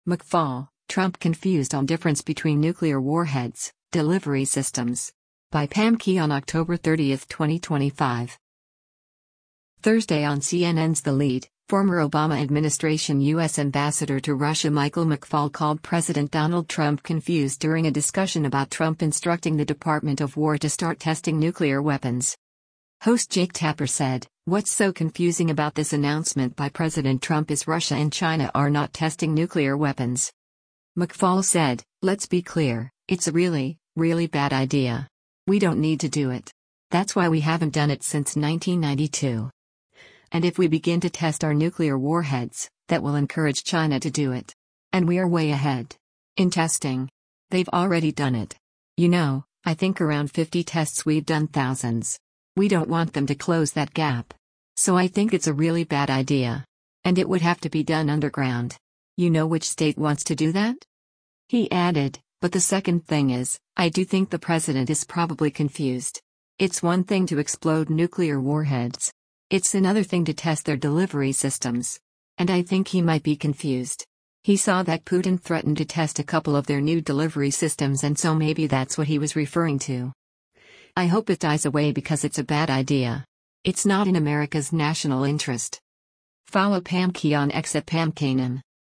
Thursday on CNN’s “The Lead,” former Obama administration U.S. Ambassador to Russia Michael McFaul called President Donald Trump “confused” during a discussion about Trump instructing the Department of War to start testing nuclear weapons.